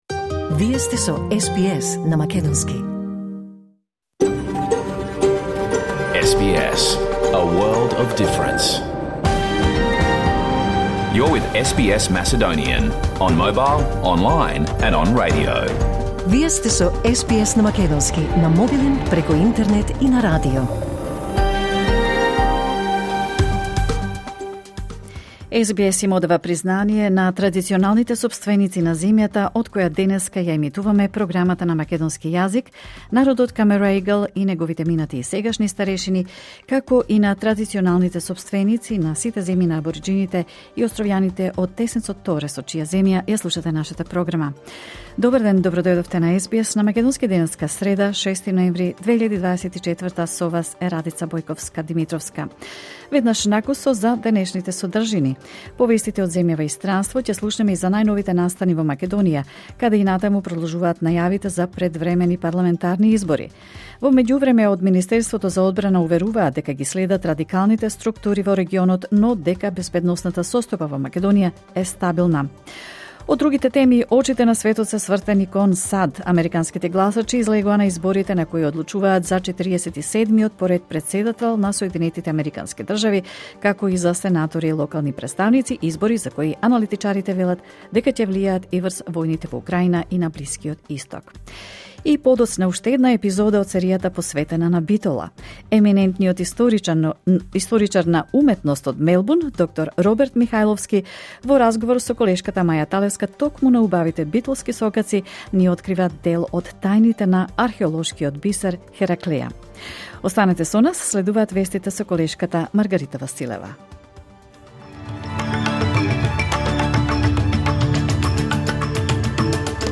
SBS Macedonian Program Live on Air 6 NOvember 2024